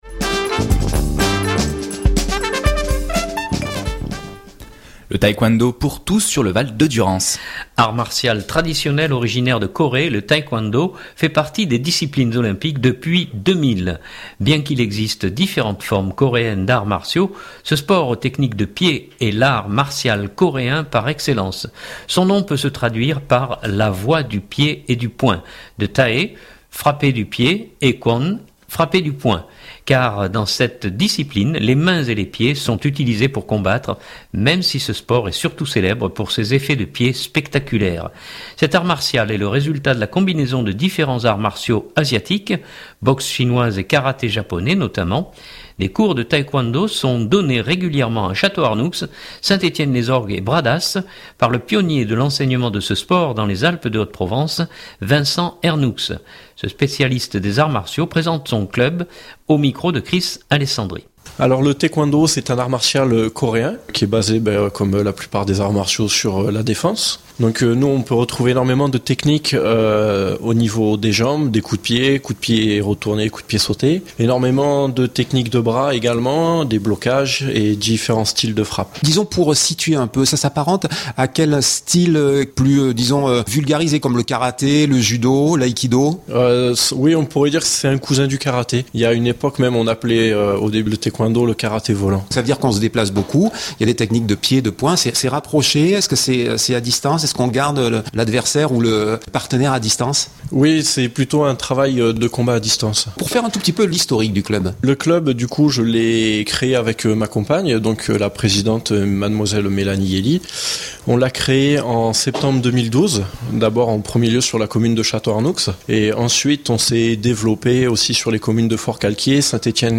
Ce spécialiste des arts martiaux présente son club